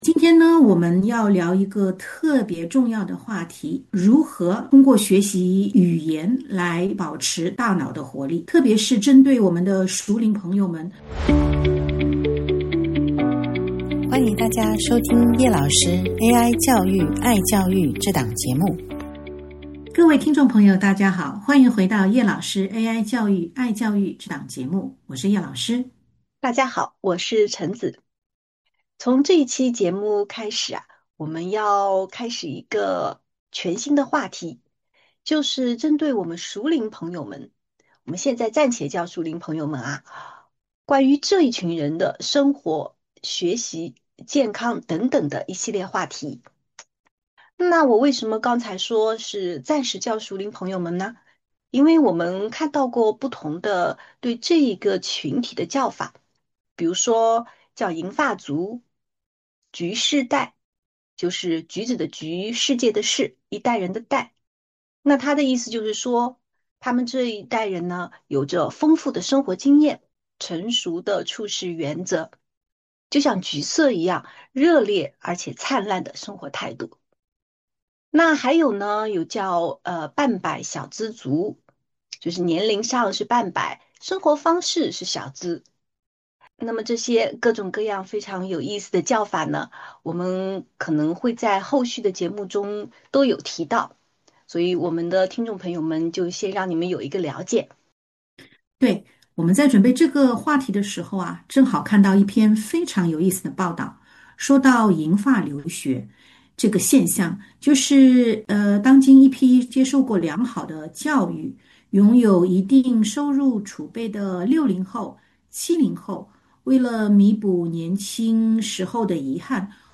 这一期节目，两位主持人聊了如何通过学习语言来保持大脑的活力，特别是针对我们的熟龄朋友们，或者说橘世代的朋友们。先从科学角度，聊了为什么学习新语言能够对抗认知衰退，保持大脑活力；然后聊了如何寻找AI驱动的语言学习应用，和六个成年学习者学习语言的核心策略：